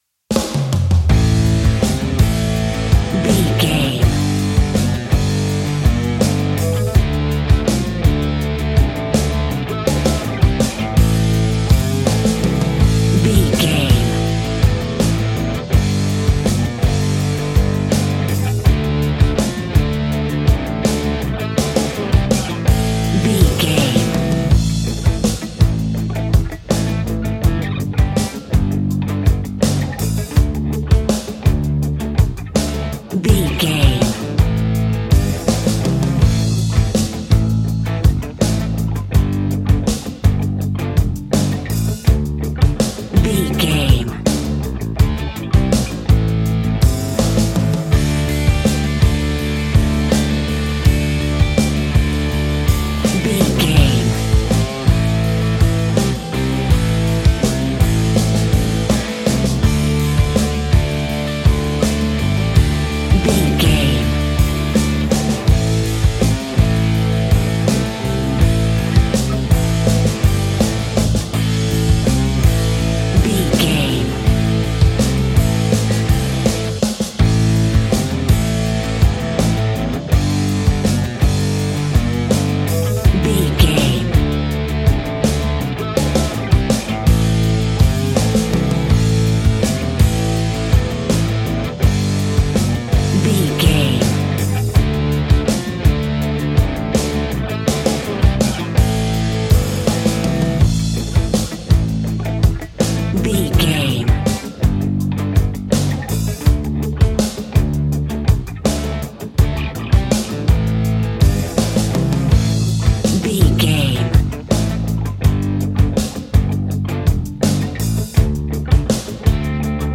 Aeolian/Minor
groovy
powerful
electric guitar
bass guitar
drums
organ